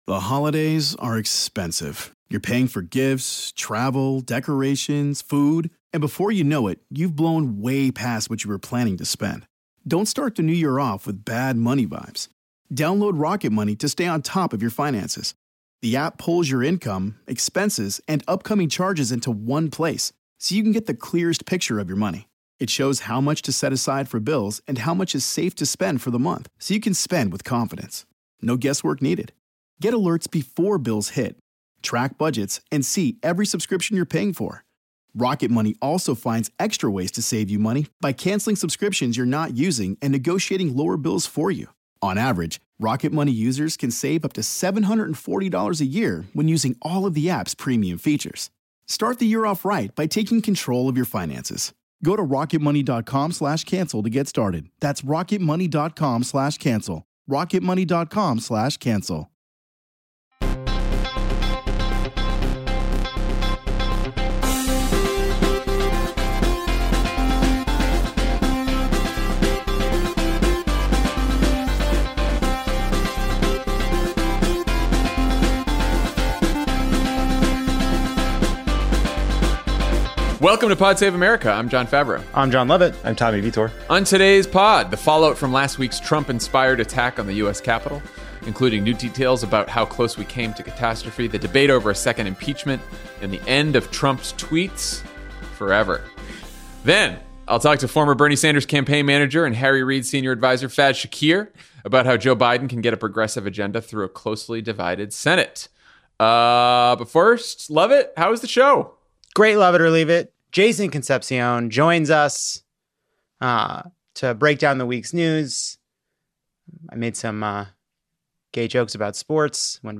The fallout continues from last week’s Trump-inspired attack on the U.S. Capitol, as the House prepares to impeach Donald Trump for the second time, and the President’s personal Twitter account is banned forever. Then former Bernie Sanders campaign manager Faiz Shakir talks to Jon Favreau about how Joe Biden can get a progressive agenda through a closely divided Senate.